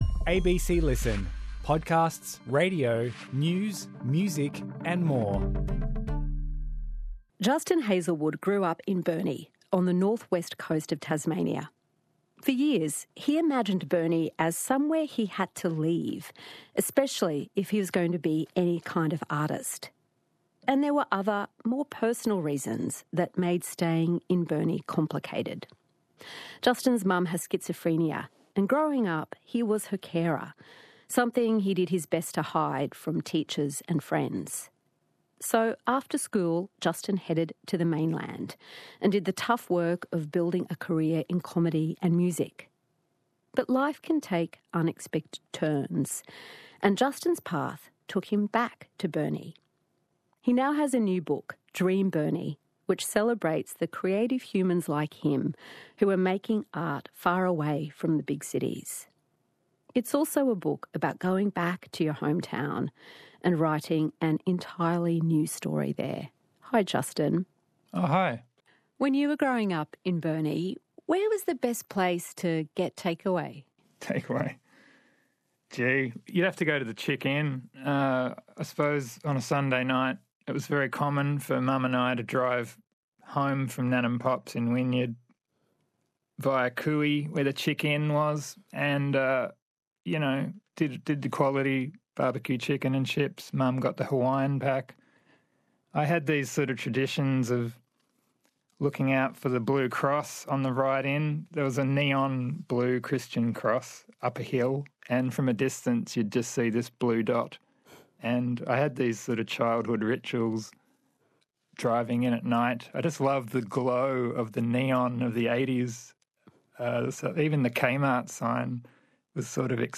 Hosted by Richard Fidler and Sarah Kanowski, Conversations is the ABC's most popular long-form interview program.